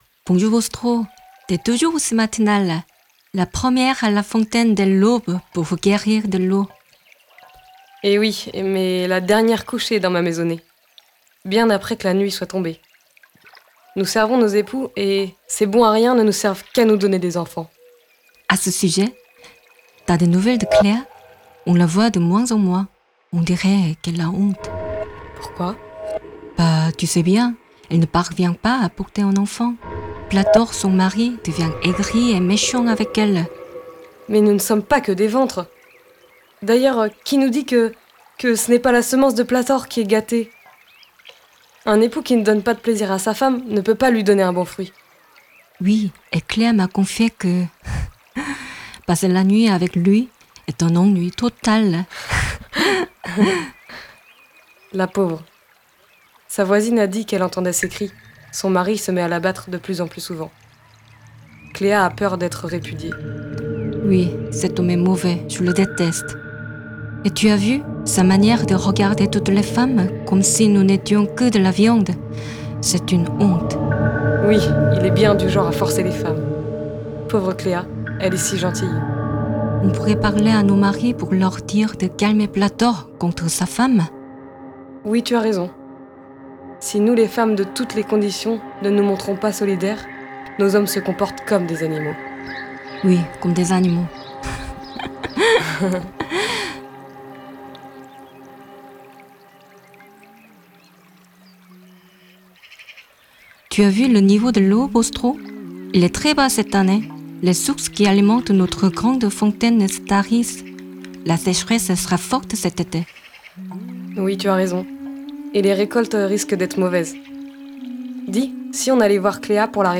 Conversation entre deux femmes – Storie Nostrum
Deux femmes discutent à la fontaine de leurs obligations, de leurs problèmes...
Pour une meilleure écoute en son stéréo, nous vous invitons à utiliser un casque.